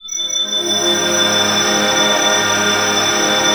SYNTH RIFF-L.wav